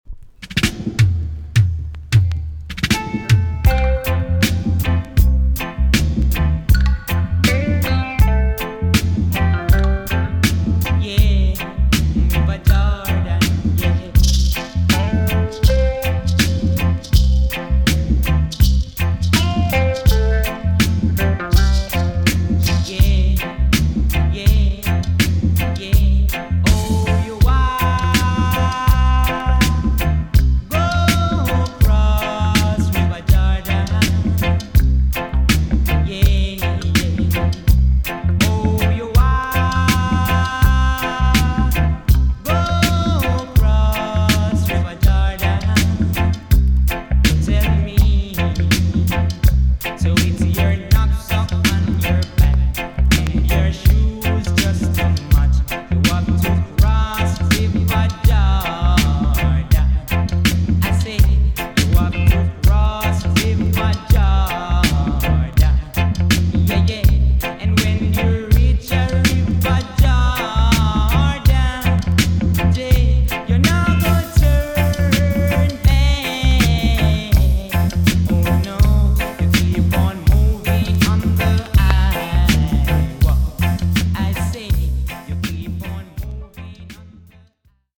TOP >DISCO45 >VINTAGE , OLDIES , REGGAE
EX- 音はキレイです。
CANADA , NICE VOCAL TUNE!!